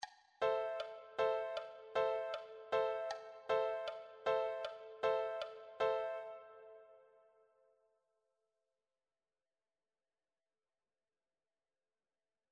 Se utiliza un silencio en los tiempos fuertes para enfatizar aún más la rotura de la acentuación natural.
Ejemplo de un acompañamiento de acordes a contratiempo.
Contratiempo con metrónomo.